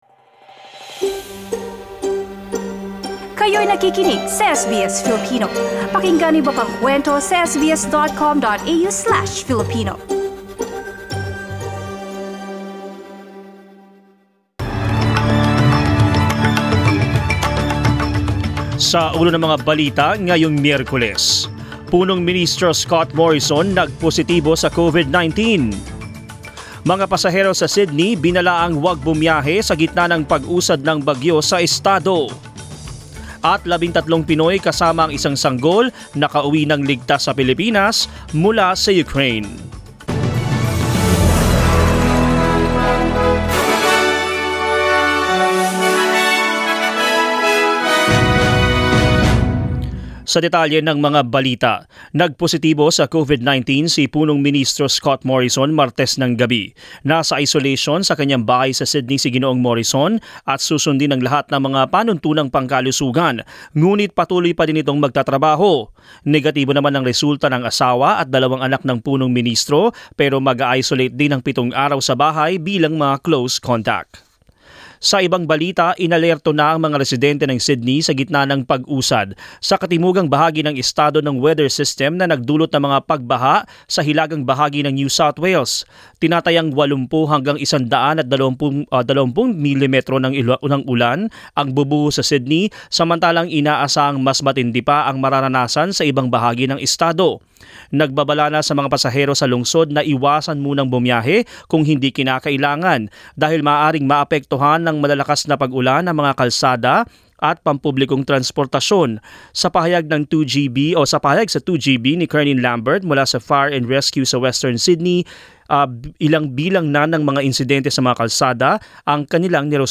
Mga balita ngayong ika-2 ng Marso